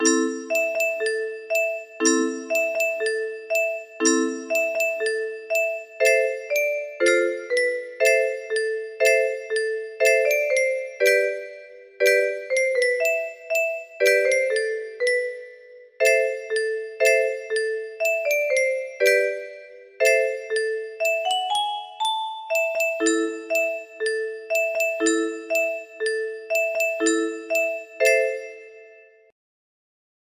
Unknown Artist - Untitled music box melody
Grand Illusions 30 (F scale)